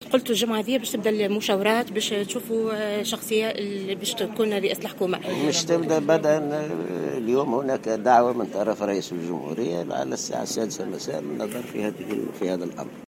قال الأمين العام للاتحاد العام التونسي للشغل في تصريح خاطف لموفدة "الجوهرة اف أم" إن المشاورات بشأن رئيس الحكومة الجديدة ستبدأ انطلاقا من اليوم الاثنين.
وقال إن منظمته تلقت دعوة من رئيس الجمهورية لحضور مشاورات بشأن هذا الأمر مساء اليوم. وجاءت تصريحاته على هامش ندوة دولية تنظمها اليوم في سوسة الجامعة العامة للنقل التابعة للاتحاد العام التونسي للشغل بالشراكة مع الاتحاد الدولي للنقل.